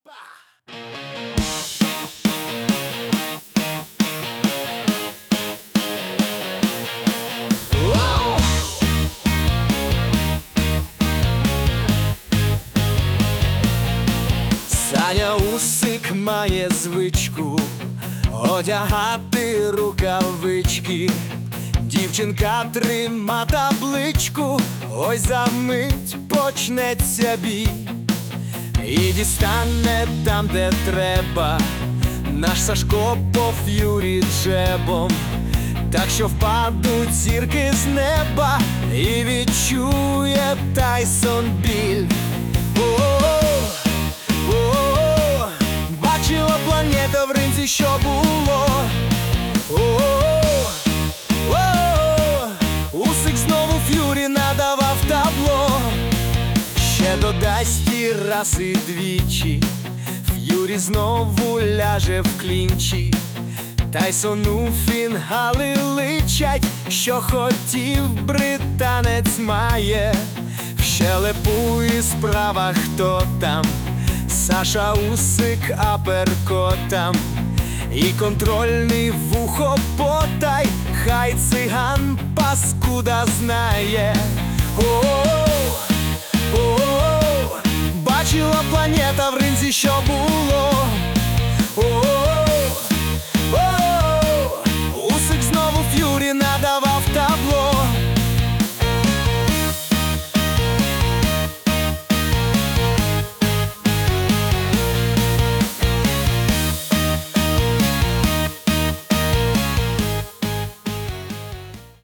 ВИД ТВОРУ: Пісня